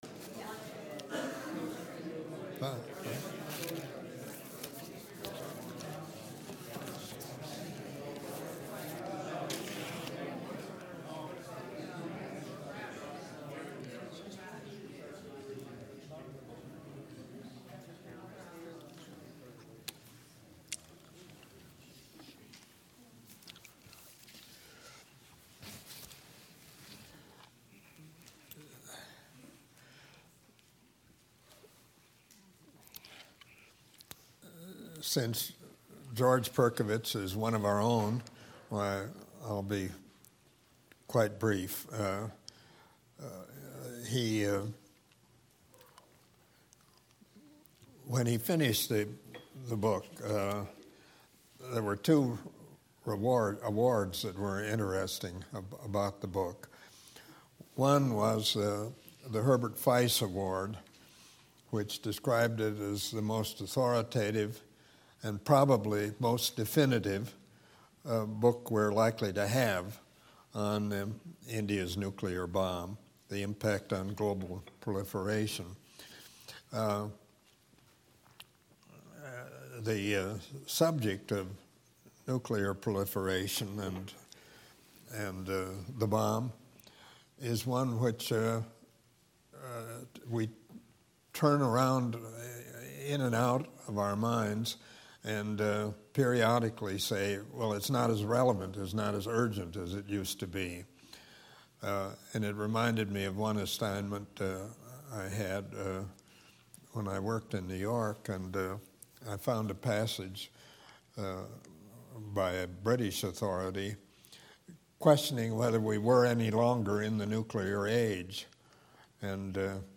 gives a lecture on nuclear proliferation and why he is optimistic that it can be contained. He argues that the state of international proliferation is not as bad as it has been in the past, as North Korea is the only hostile state believed to posses a nuclear weapon and Iran is the only hostile country with a current nuclear program.